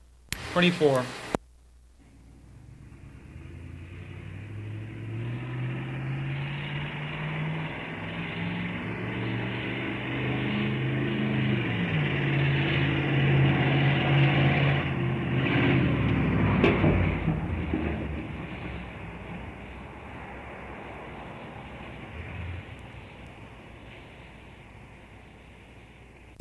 老式卡车 " G1424皮卡车
描述：卡车来自远方，驾驶速度非常快。较小的卡车，可用于民用车辆。一些风噪声。 这些是20世纪30年代和20世纪30年代原始硝酸盐光学好莱坞声音效果的高质量副本。 40年代，在20世纪70年代早期转移到全轨磁带。我已将它们数字化以便保存，但它们尚未恢复并且有一些噪音。
标签： 卡车 交通运输 光学 经典
声道立体声